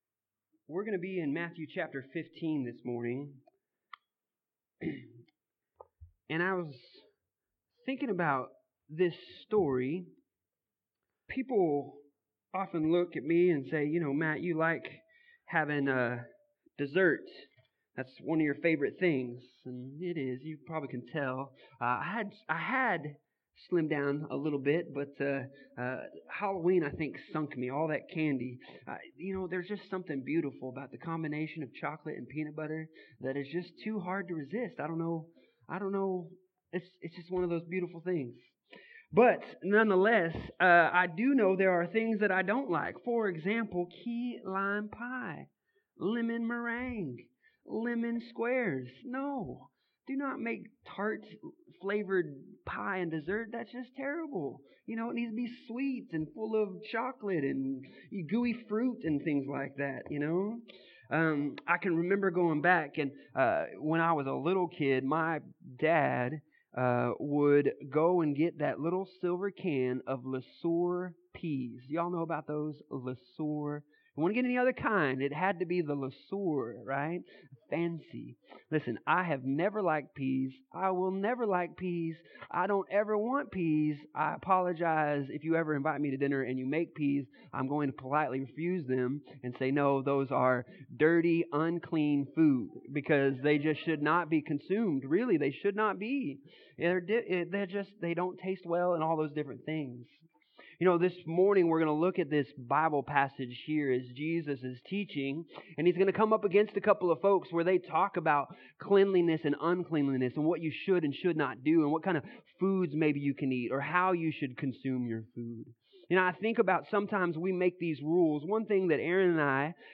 Passage: Matthew 15:1-20 Service Type: Sunday Morning